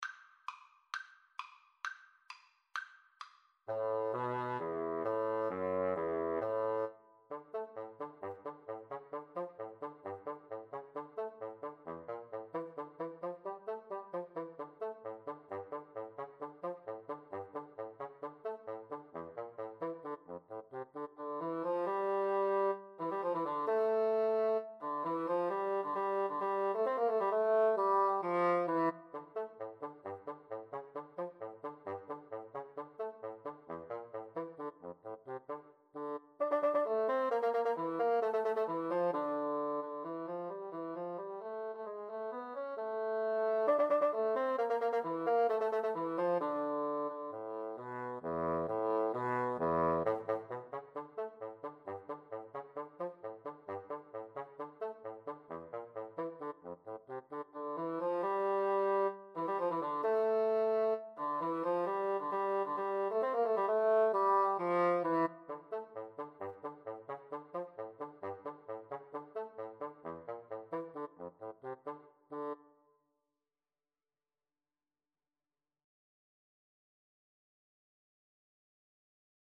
Free Sheet music for Clarinet-Bassoon Duet
D minor (Sounding Pitch) (View more D minor Music for Clarinet-Bassoon Duet )
2/4 (View more 2/4 Music)
Traditional (View more Traditional Clarinet-Bassoon Duet Music)